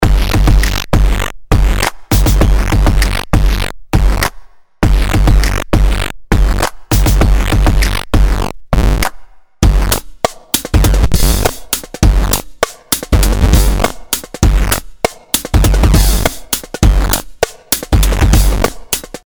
Hier habe ich die Samplerate moduliert. Die Bit-Tiefe steht bei 3.
Derart karrend und knirschend macht eine Lo-Fi-Bassdrum richtig Spaß!